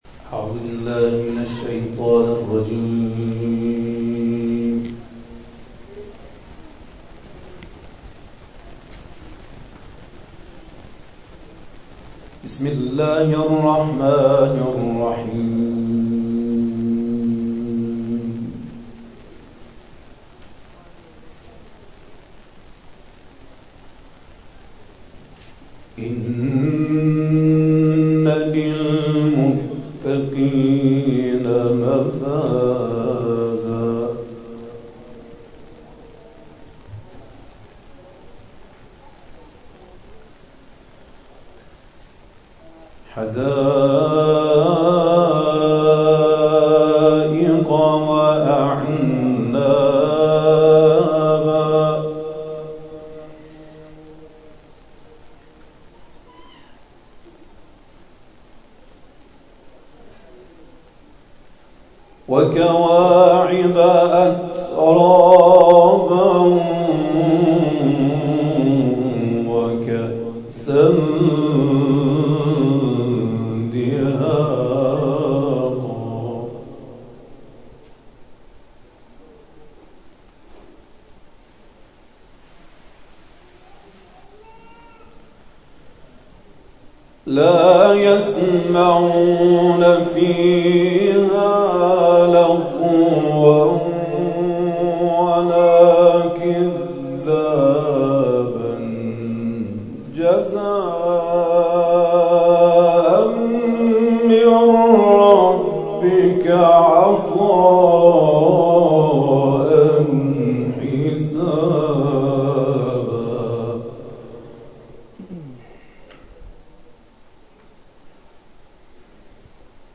کرسی‌های تلاوت نفحات‌القرآن